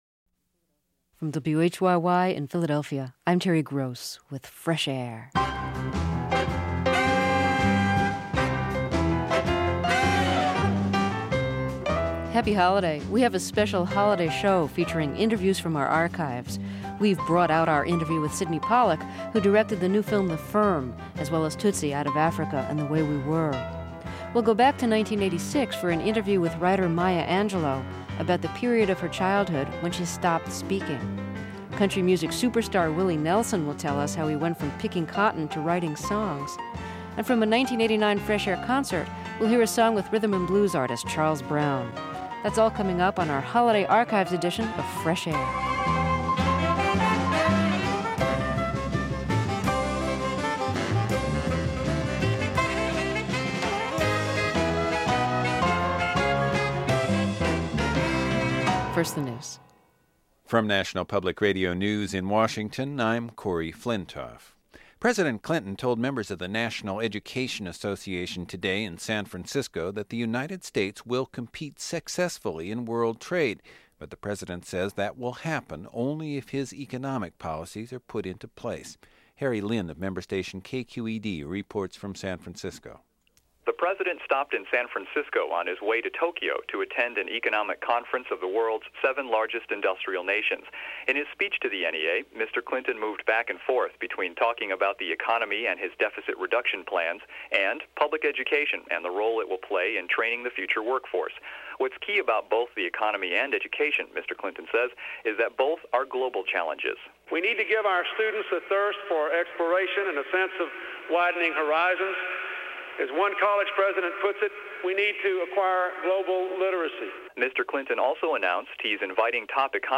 | Fresh Air Archive: Interviews with Terry Gross